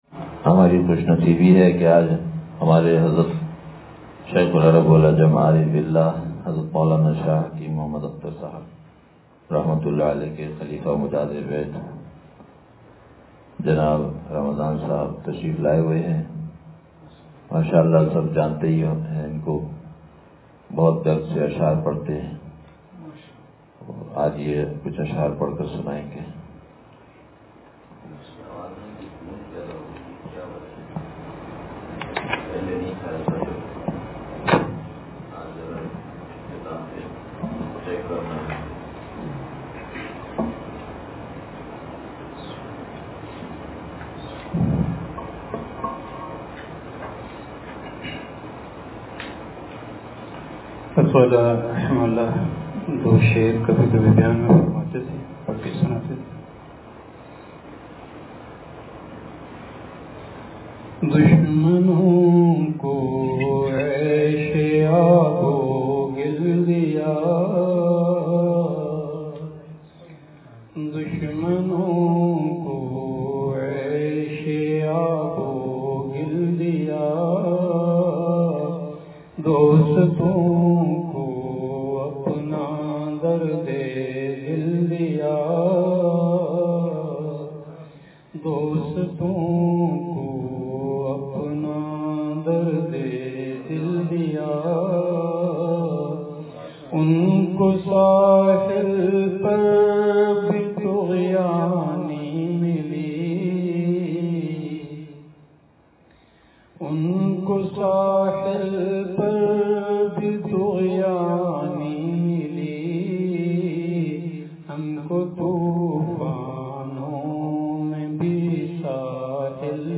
اشعار